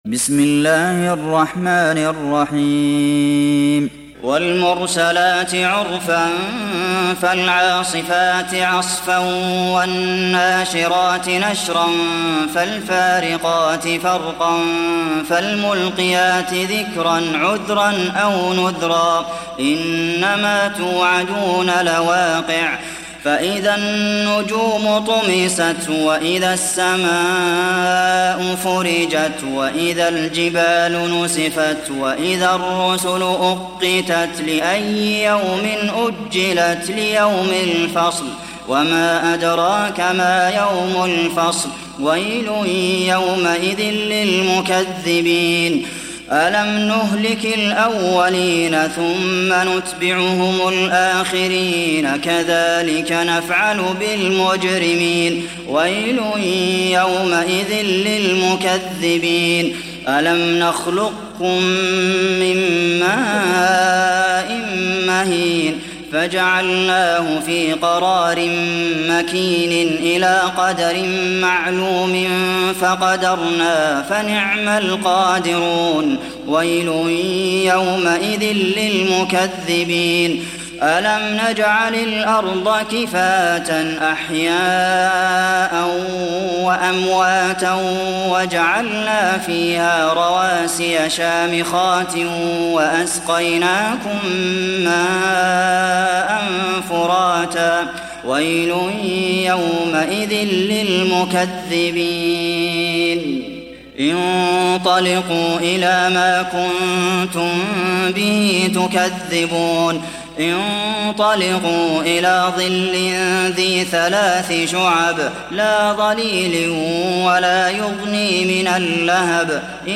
دانلود سوره المرسلات mp3 عبد المحسن القاسم (روایت حفص)